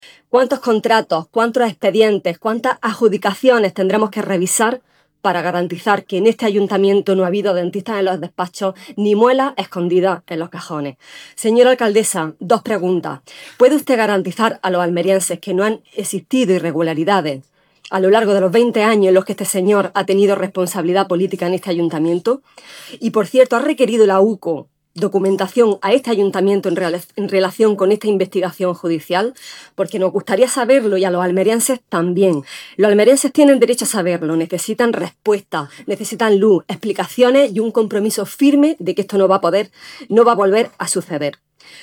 La portavoz del PSOE en el Ayuntamiento de Almería, Fátima Herrera, ha solicitado hoy en el Pleno la realización de una auditoría externa y exhaustiva que revise todos los contratos vinculados con el ya exconcejal del PP Javier Aureliano García durante su etapa como edil del equipo de Gobierno en el Consistorio, concretamente desde el año 2003. Herrera ha subrayado que esta medida es imprescindible para garantizar la transparencia y disipar cualquier sombra de duda sobre la gestión llevada a cabo en los últimos años.